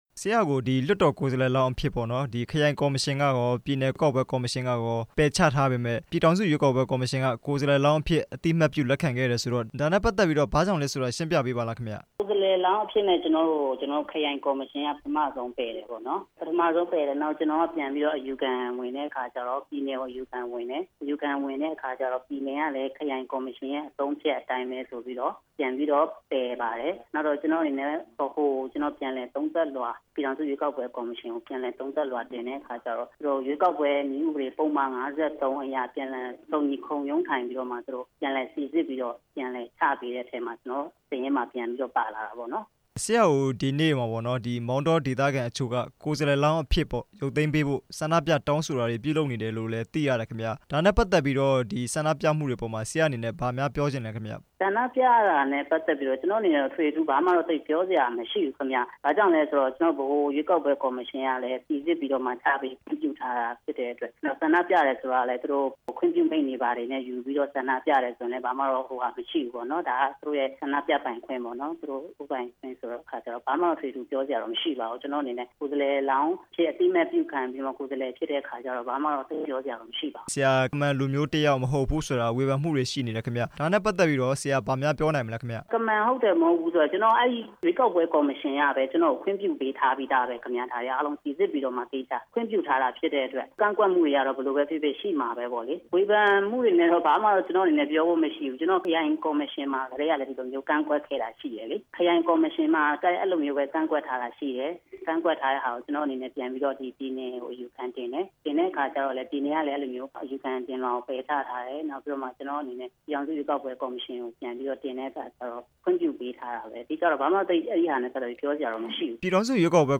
ဒီမိုကရေစီနဲ့ လူ့အခွင့်အရေးပါတီ ကိုယ်စားလှယ်လောင်းနဲ့ မေးမြန်းချက်